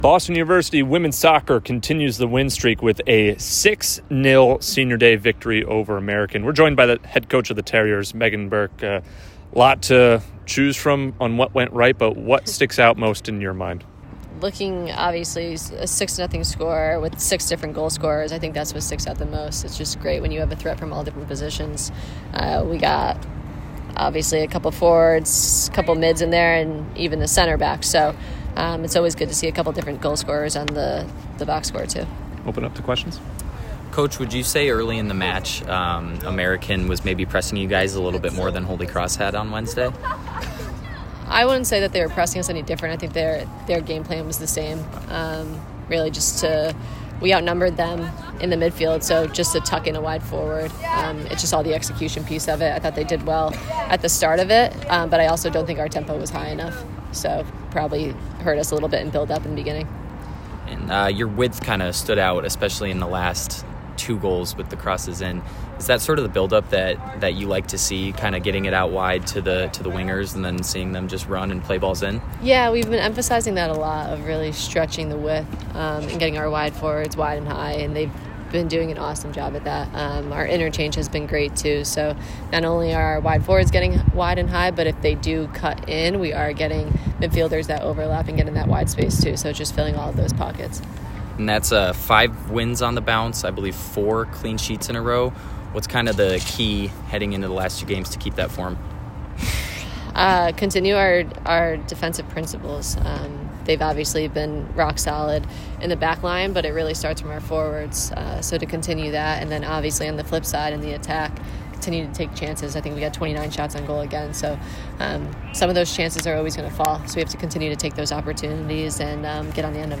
Women's Soccer / American Postgame Interviews